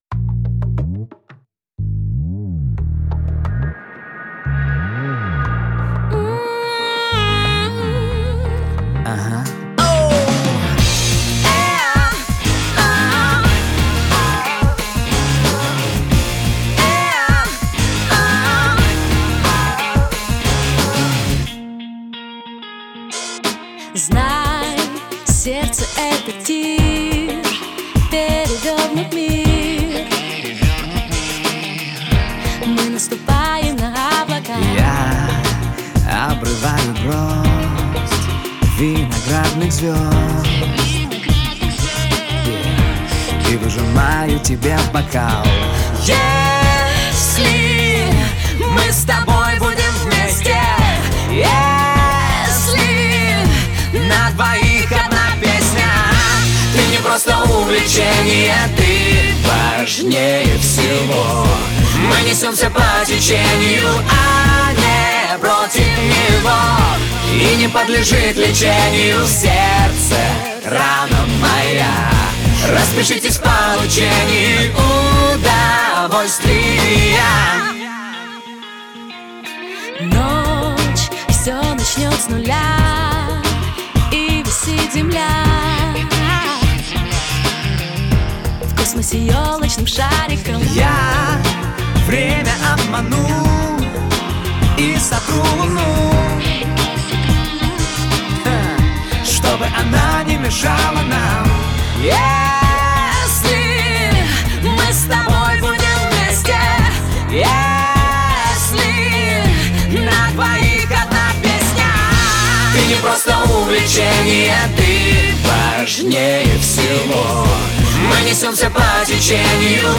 Жанр: Pop